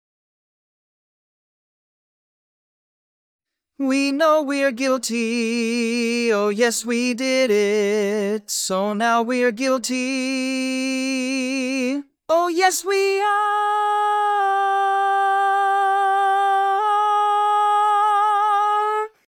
Key written in: D♭ Major
Type: Barbershop
Each recording below is single part only.